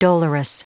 Prononciation du mot dolorous en anglais (fichier audio)
Prononciation du mot : dolorous